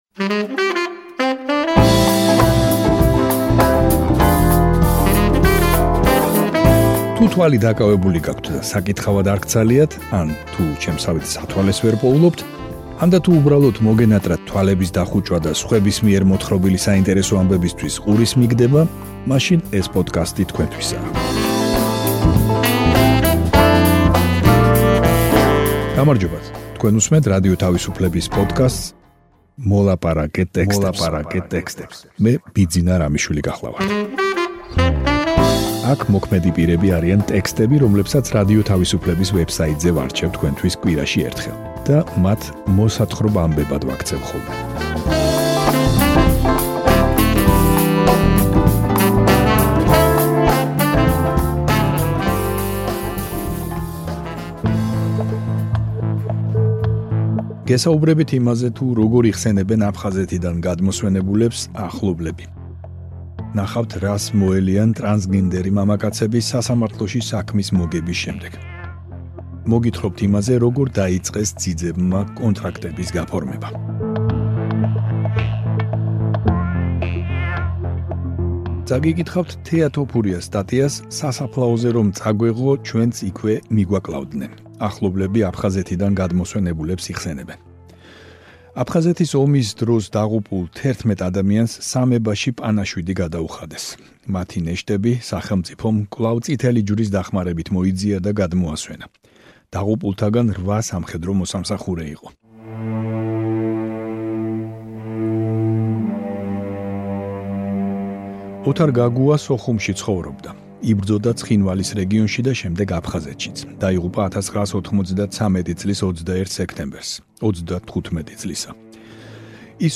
თუ თვალი დაკავებული გაქვთ და საკითხავად არ გცალიათ, ან თუ სათვალეს ვერ პოულობთ, ანდა, თუ უბრალოდ მოგენატრათ თვალების დახუჭვა და სხვების მიერ მოთხრობილი საინტერესო ამბებისთვის ყურის მიგდება, მაშინ ეს პოდკასტი თქვენთვისაა.